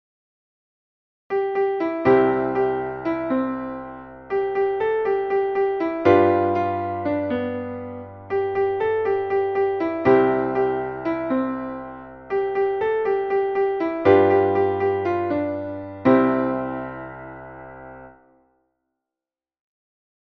Traditionelles Kinder-/ Kirchenlied